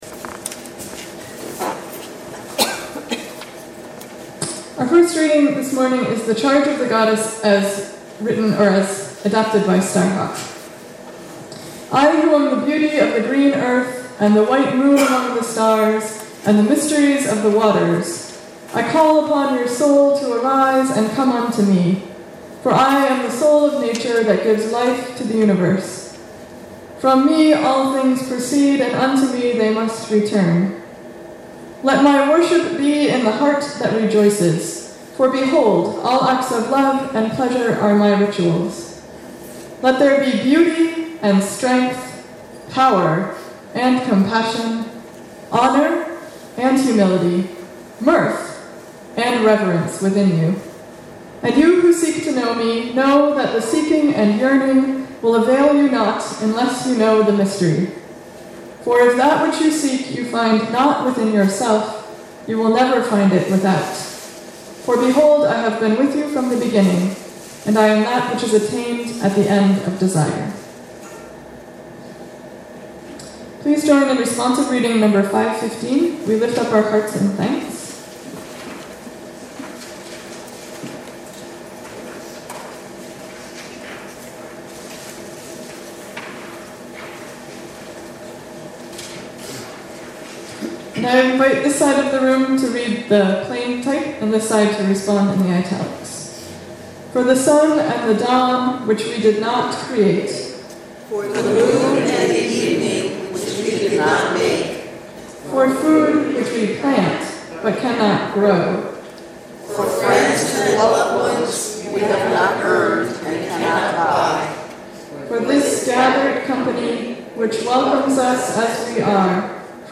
Click the arrow below to hear the readings and the sermon
Posted in Sermons | Leave a Comment »